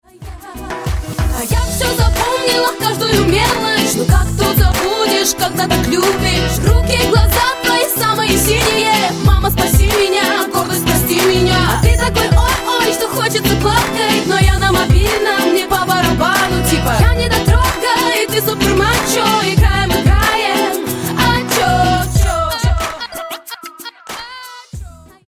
Главная » рингтоны на телефон » Рэп, Хип-Хоп, R'n'B